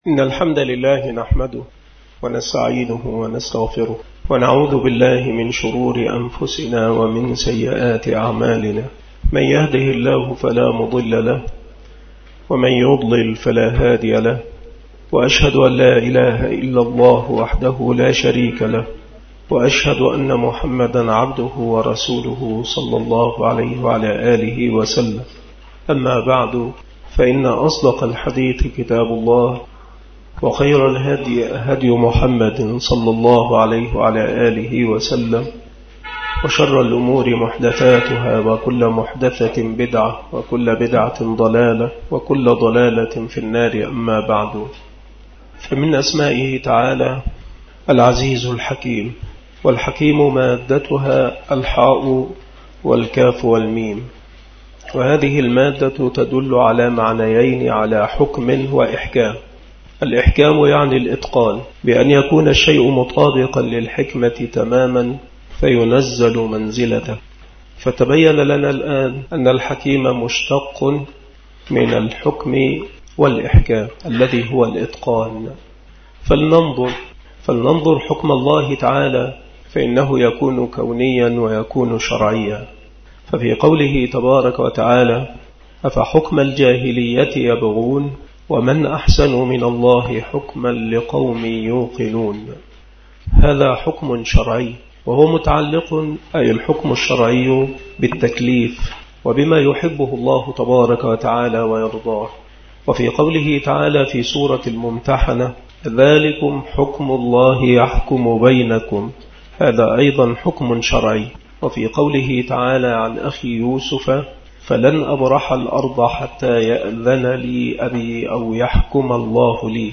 مكان إلقاء هذه المحاضرة بالمسجد الشرقي بسبك الأحد - أشمون - محافظة المنوفية – مصر